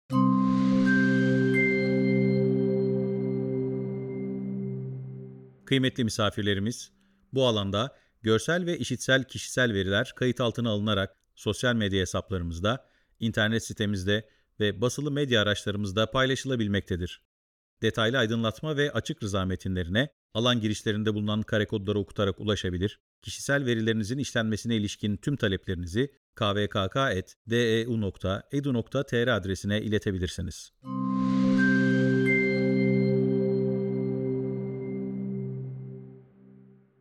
KVKK Bilgilendirme Anonsu
KVKK_Bilgilendirme_Anonsu-3.mp3